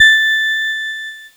Cheese Note 27-A4.wav